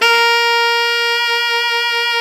SAX ALTOFF0E.wav